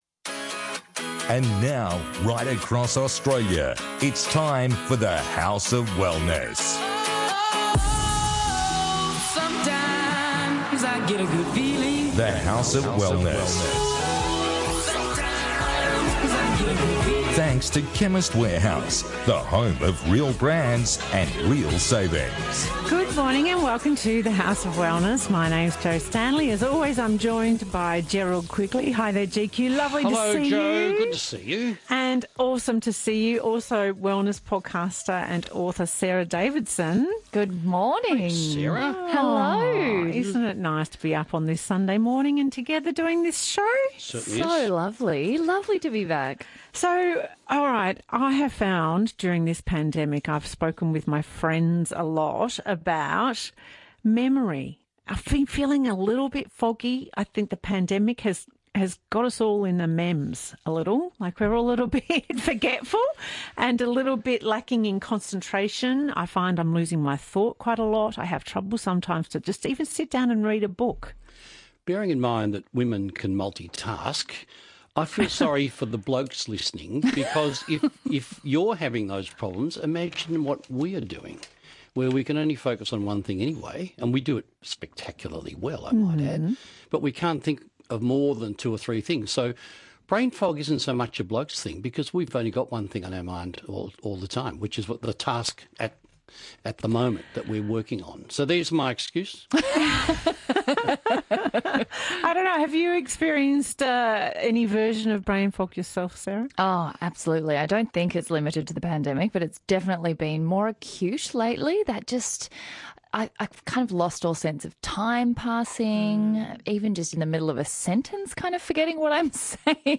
Get well, stay well, live well and look fabulous with The House of Wellness radio show.